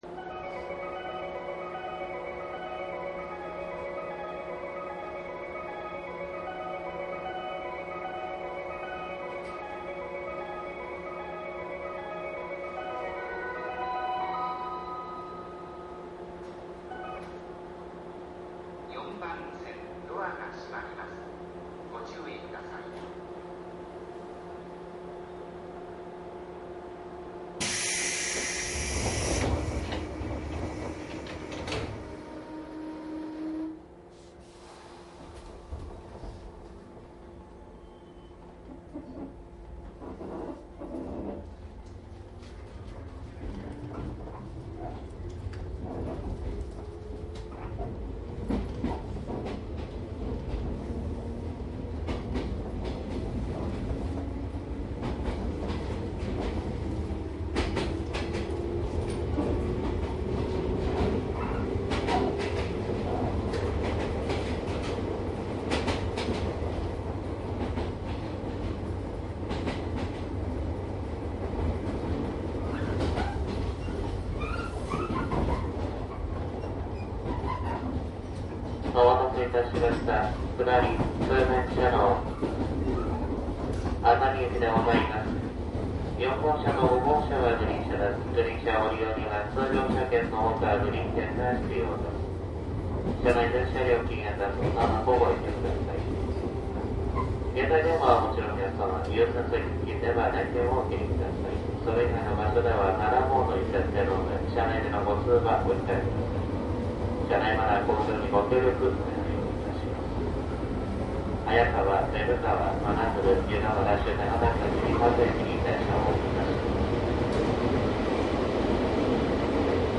商品説明  ♪ＪＲ東海道線113系鉄道走行音 ＣＤ ♪
2000番台と1000番台による微妙な違いなどが楽しめます。
DATのSPモードで録音（マイクＥＣＭ959）で、これを編集ソフトでＣＤR化したものです。